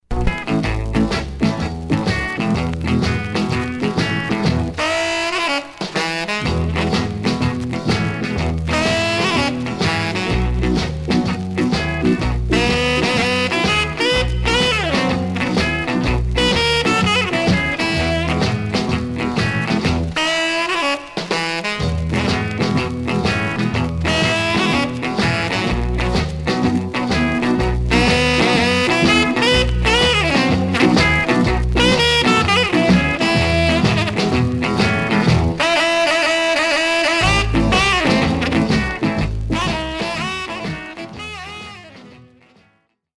Surface noise/wear
Mono
R&B Instrumental